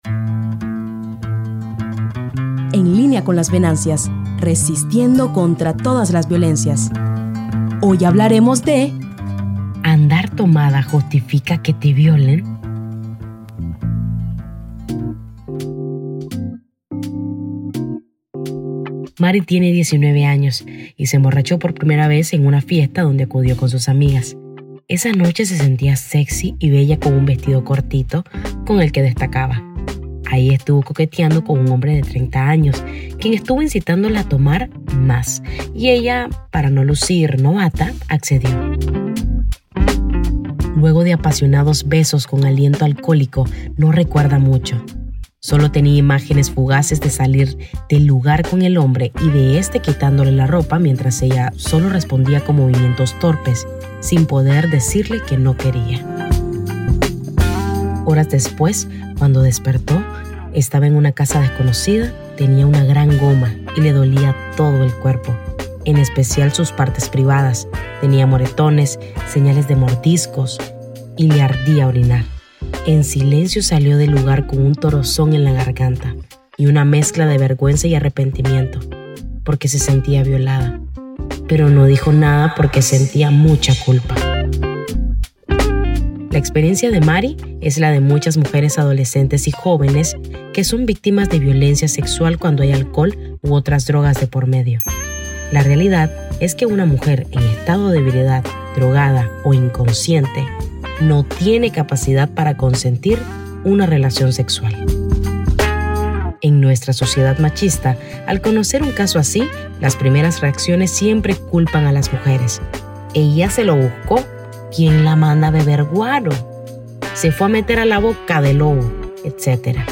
Musicalización y recursos sonoros:
Música y efectos de la biblioteca de sonidos de uso libre de Meta: